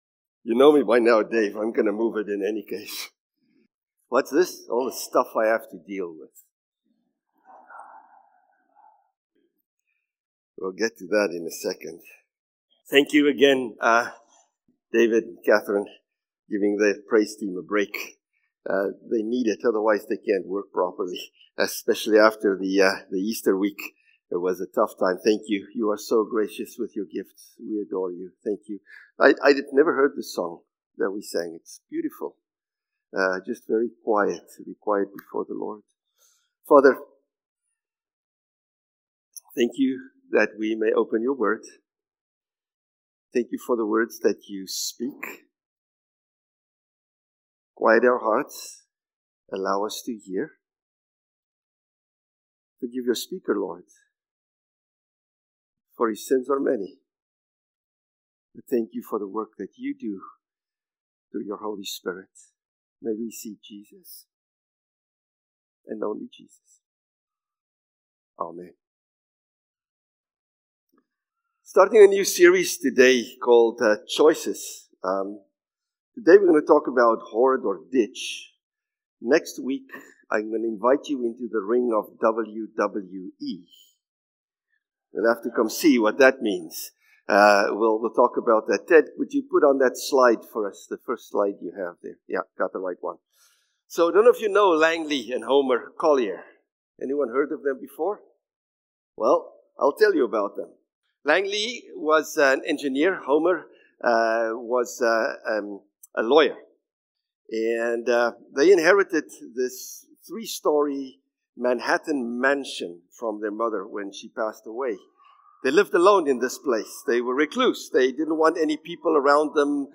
April-27-Sermon.mp3